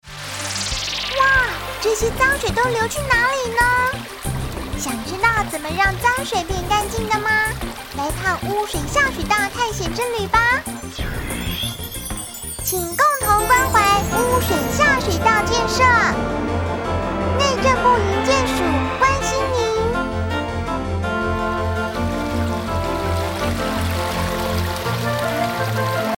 國語配音 女性配音員
小女生__內政部營建署宣導